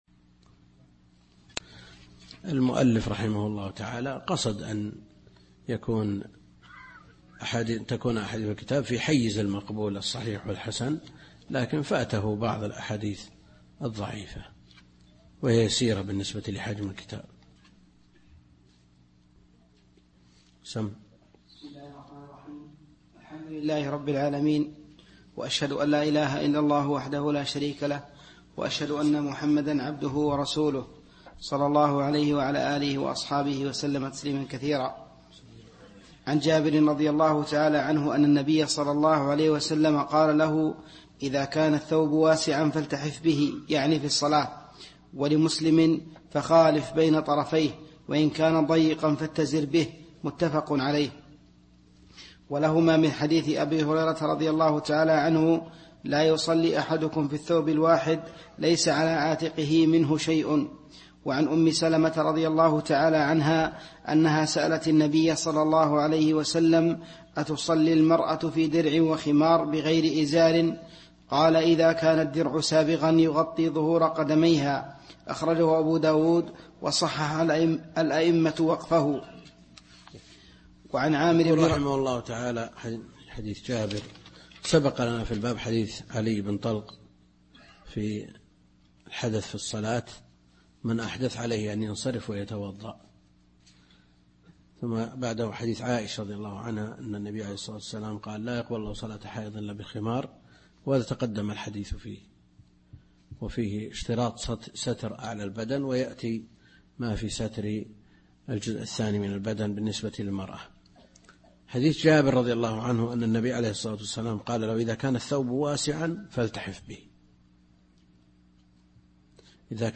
الدرس الخامس من دروس شرح بلوغ المرام كتاب الصلاة للشيخ عبد الكريم الخضير